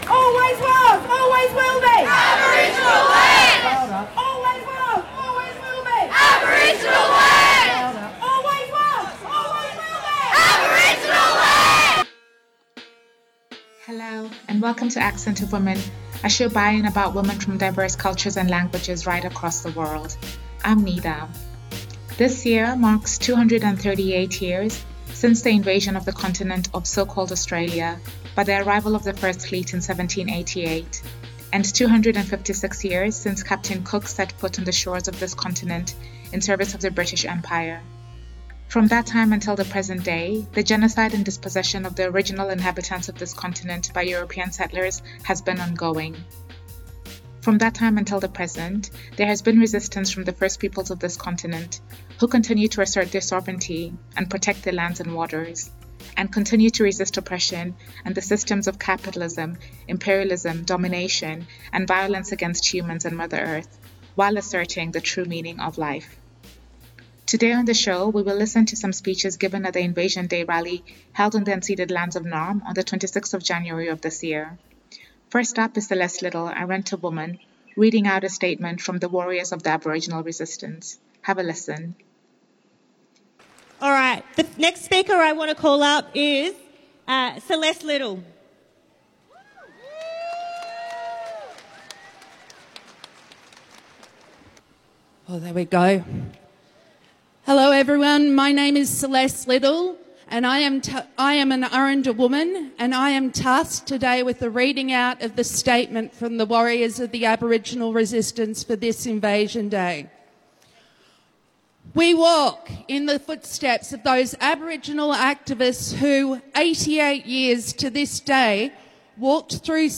Invasion Day Rally Naarm